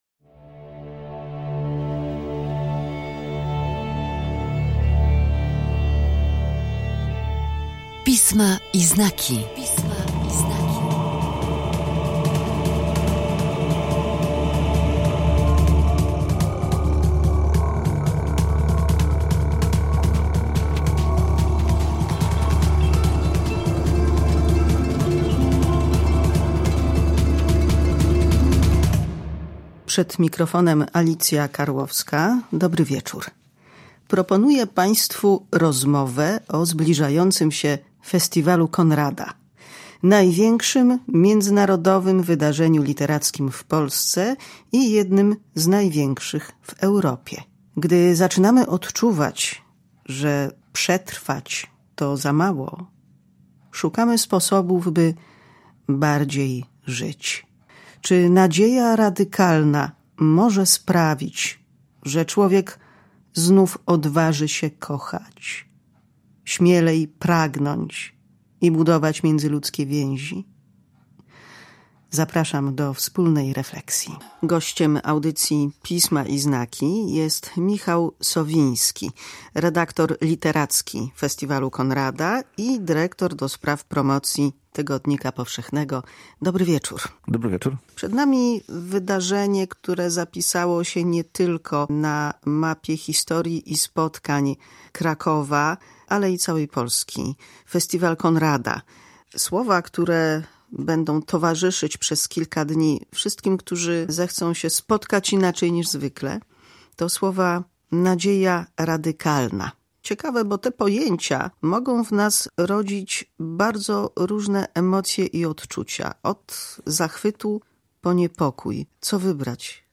W audycji Pisma i znaki rozmawiamy o nadziei radykalnej. Na czym polega, co może być dla niej inspiracją i trwałym zakorzenieniem? Dlaczego warto zaufać literaturze?